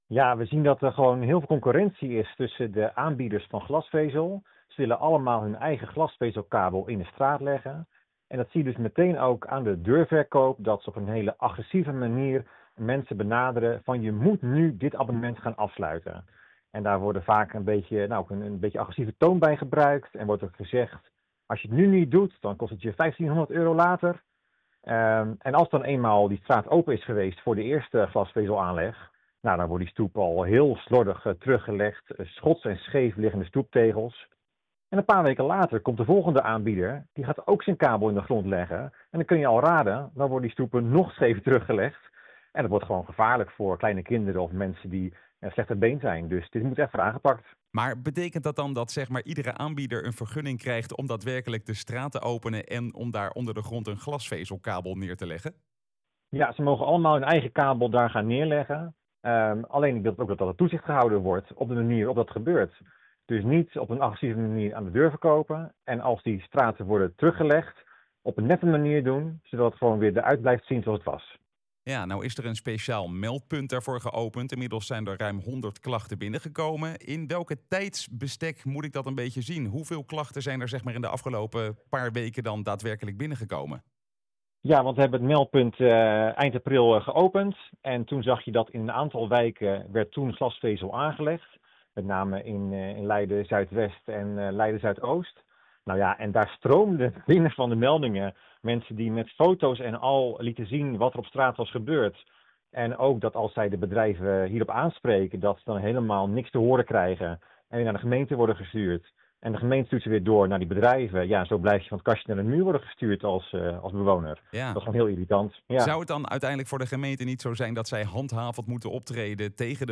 in gesprek met CDA-fractievoorzitter Sebastiaan van der Veer